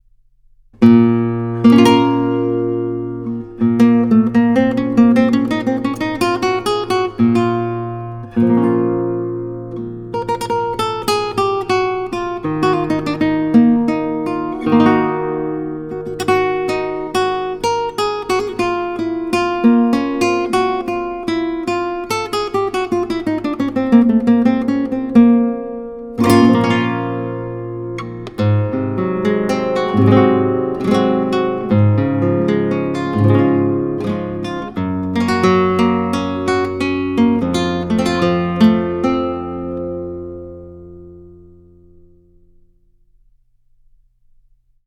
Classical Guitarist
Flamenco